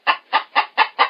PixelPerfectionCE/assets/minecraft/sounds/mob/chicken/say3.ogg at mc116